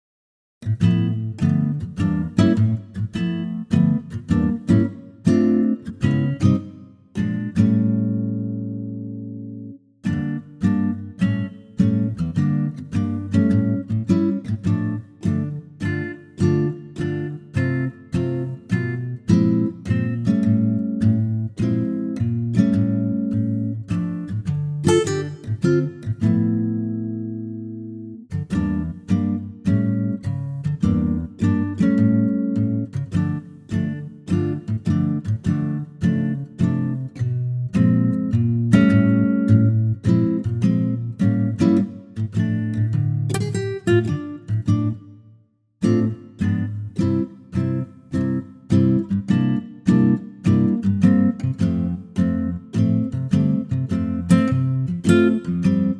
key - Dm - vocal range - D to D